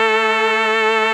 54_23_organ-A.wav